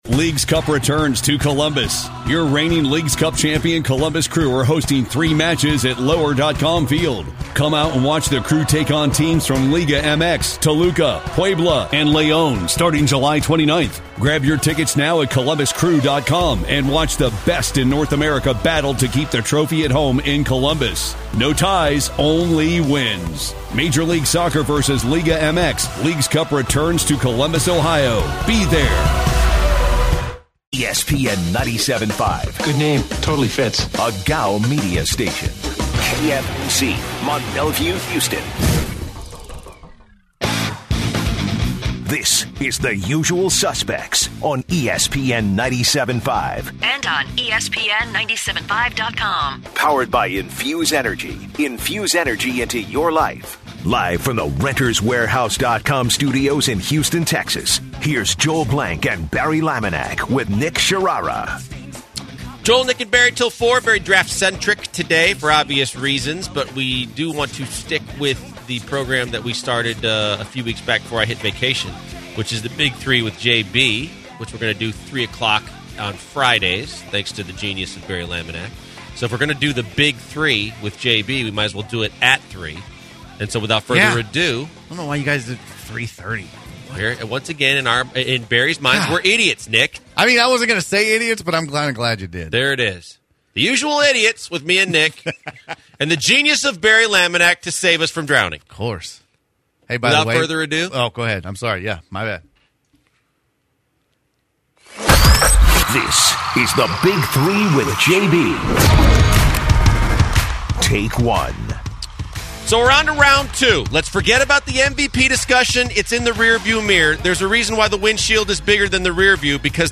In the last hour, the crew talks NBA playoffs and the callers weigh in on the Rockets-Spurs matchup